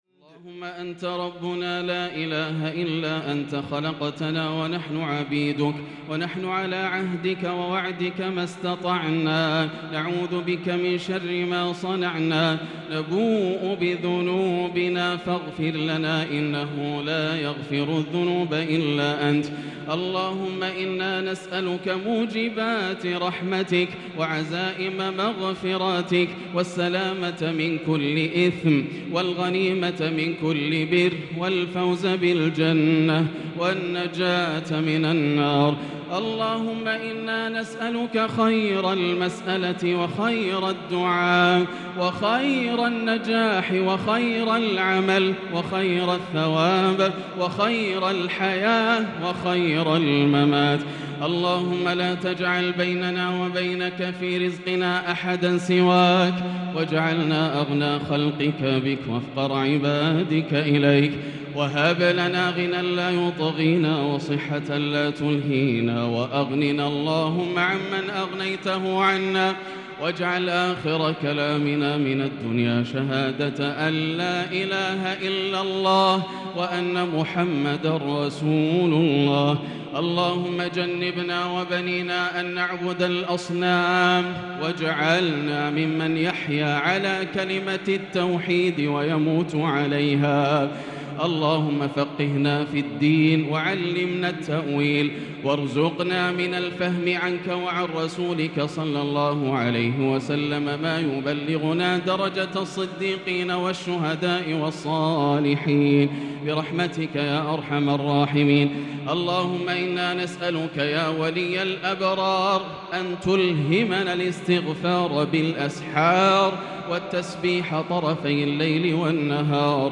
دعاء القنوت ليلة 11 رمضان 1443هـ | Dua for the night of 11 Ramadan 1443H > تراويح الحرم المكي عام 1443 🕋 > التراويح - تلاوات الحرمين